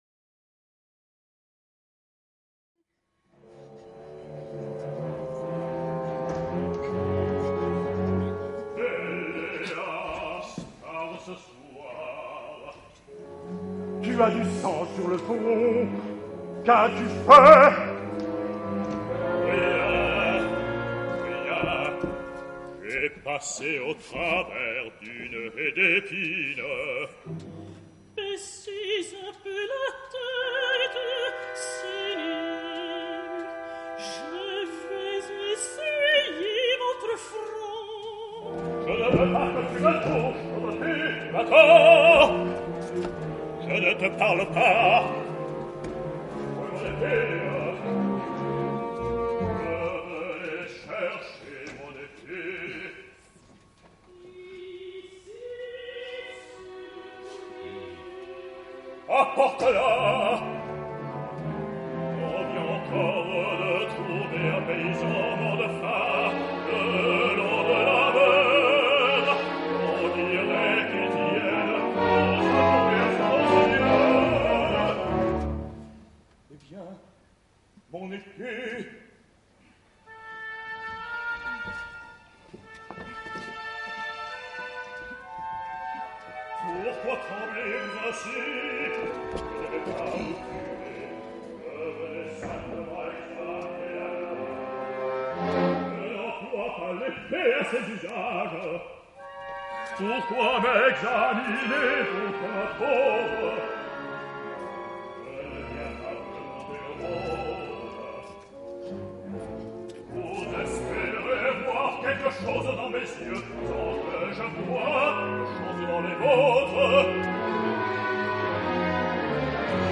baryton
OPERA